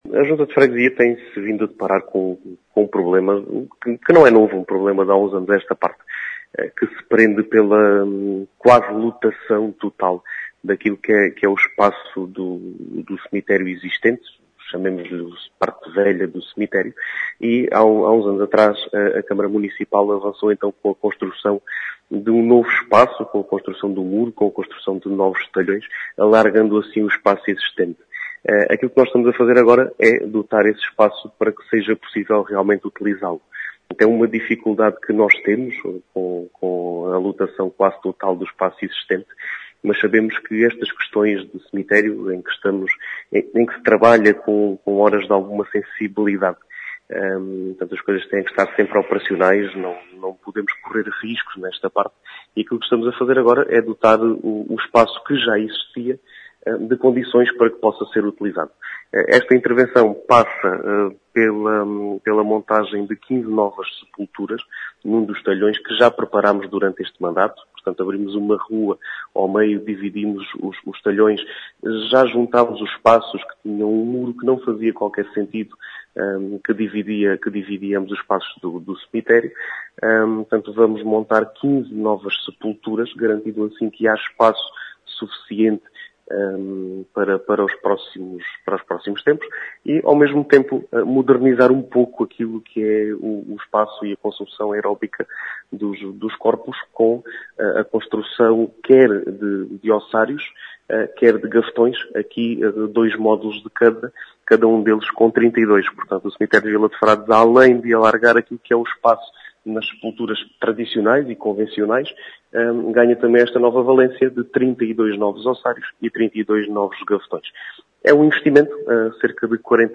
As explicações são do presidente da junta de freguesia de Vila de Frades, Diogo Conqueiro, fala num “problema de lotação” neste equipamento da freguesia.